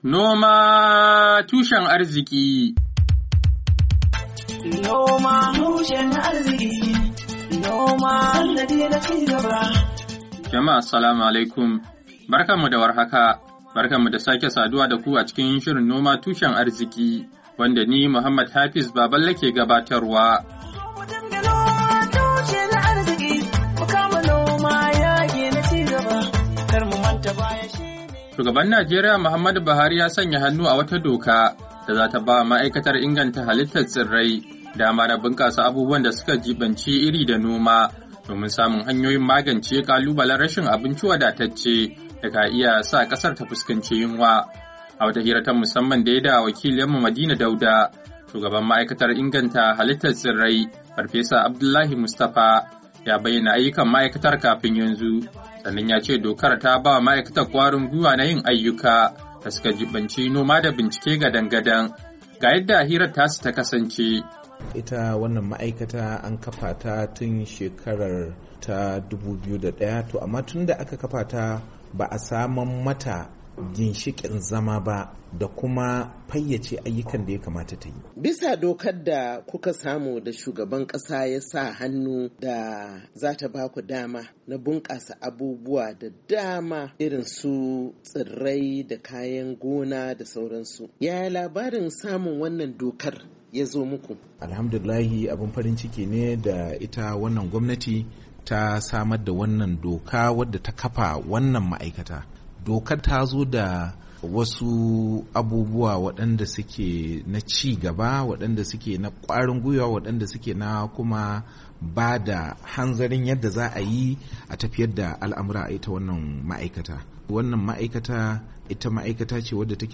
NOMA TUSHEN ARZIKI: Hira Da Shugaban Ma'aikatar Inganta Halittar Tsirrai Ta Najeriya Kan Ayyukan Da Suke Yi, Kashi Na Daya, Agusta 9, 2022